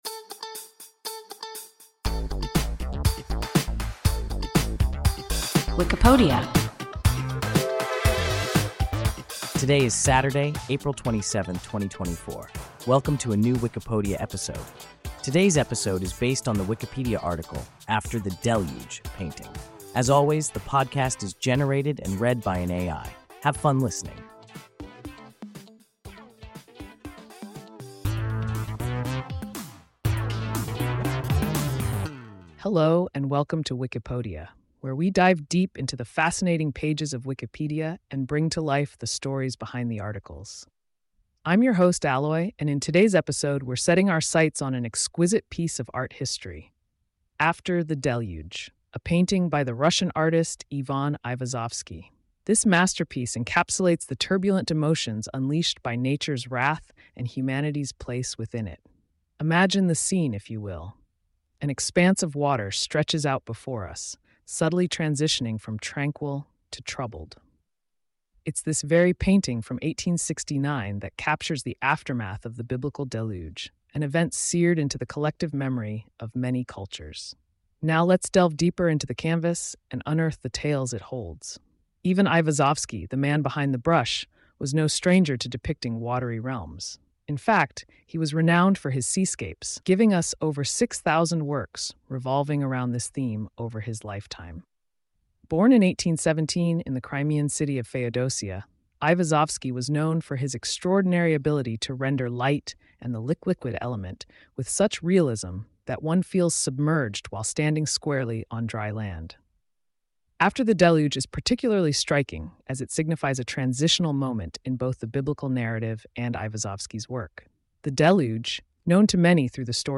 After the Deluge (painting) – WIKIPODIA – ein KI Podcast